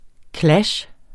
Udtale [ ˈklaɕ ]